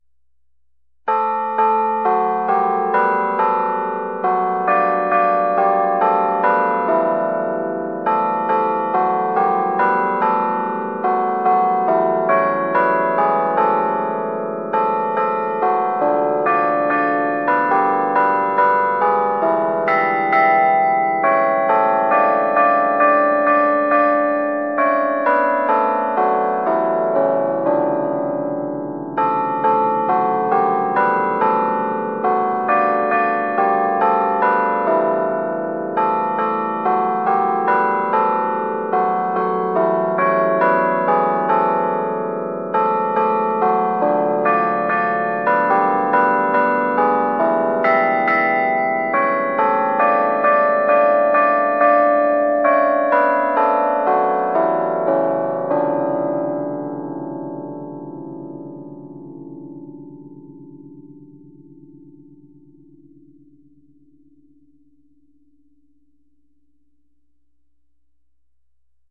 Our carillon controllers use real bell melodies.
• Realistic digital bell sounds that replicate traditional carillons